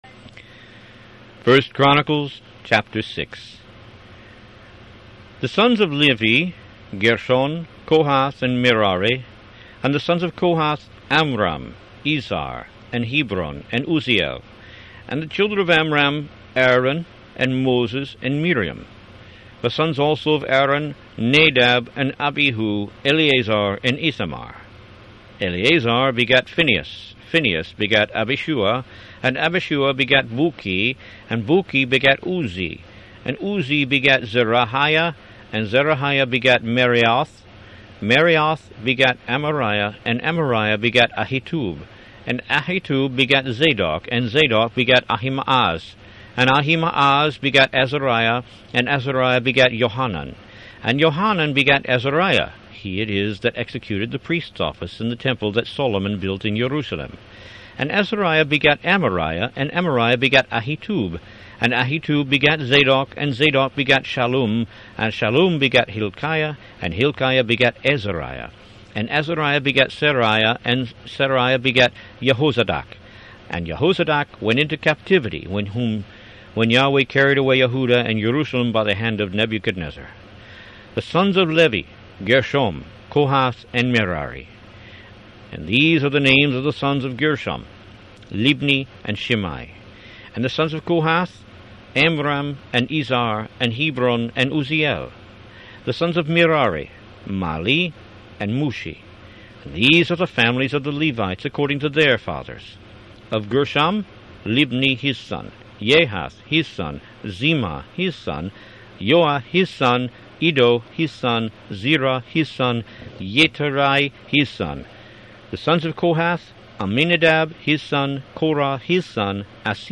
Root > BOOKS > Biblical (Books) > Audio Bibles > Tanakh - Jewish Bible - Audiobook > 13 1Chronicles